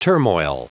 Prononciation du mot turmoil en anglais (fichier audio)
Prononciation du mot : turmoil